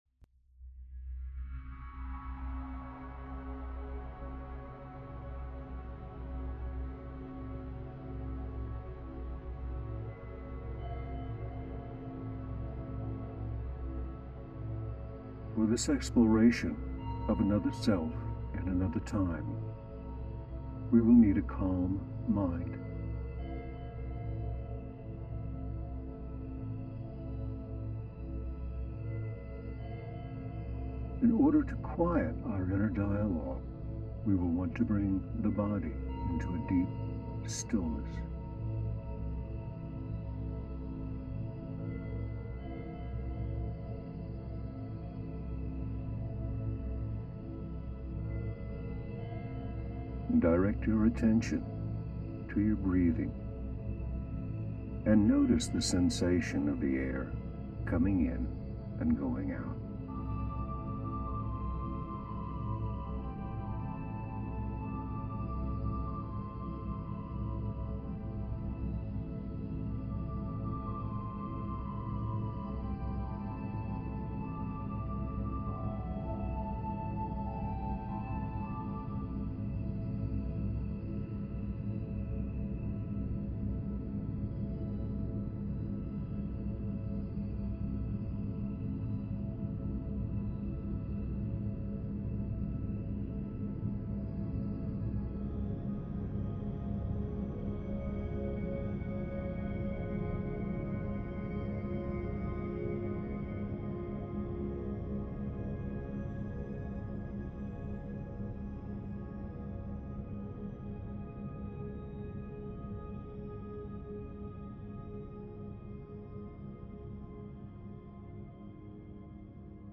Hemi-Sync® le frequenze ti aiutano ad andare oltre il tempo e lo spazio per rilassarti profondamente nella quiete mentre espandi la tua coscienza e ottieni intuizioni sullo scopo della tua vita.